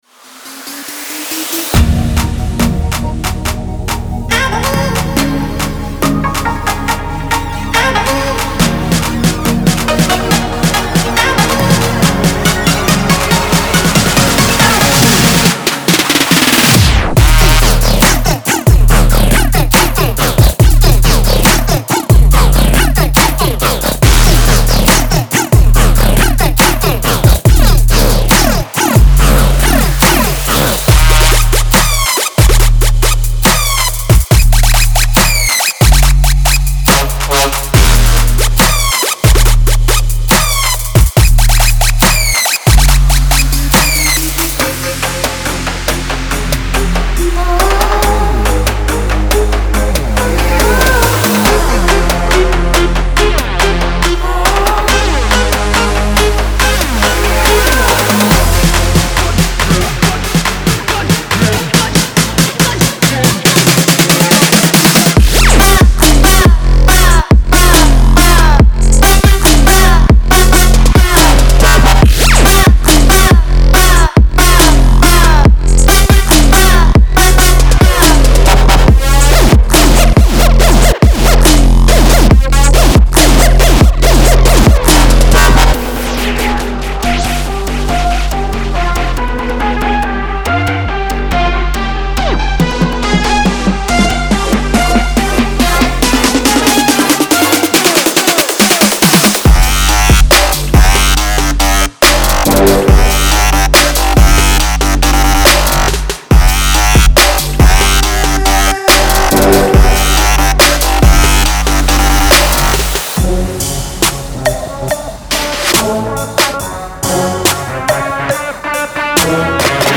• 50 Complex Bass Loops
• 30 Vocal Loops